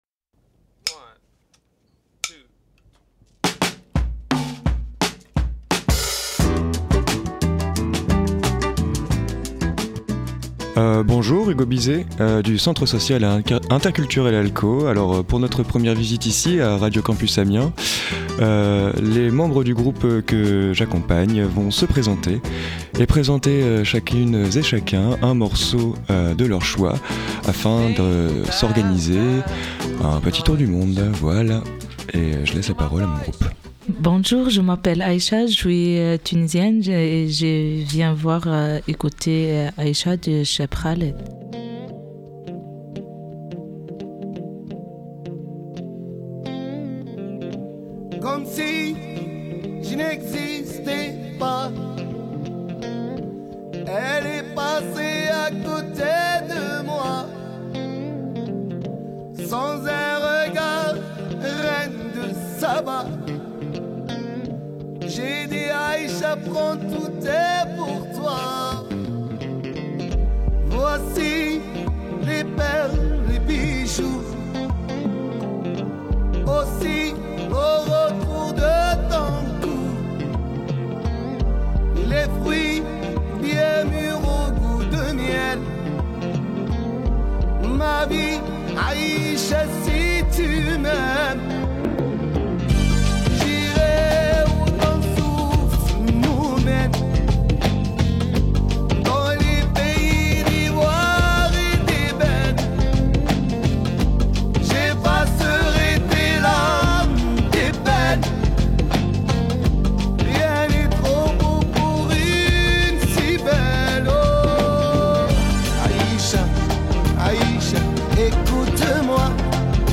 A l’issue de cette visite, une mini-émission a été réalisée. Les différentes personnes présentes lors de l’atelier ont choisi de nous partager de la musique !